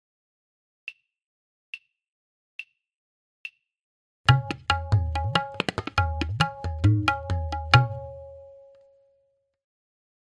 For our 5-matra phrase, we can use the main bol (theme) plus dha (shown here in 4x speed):
5-matra phrase from kayda
DK2-DhatidhagiNadhatirakita-MB_Mukhra-click.mp3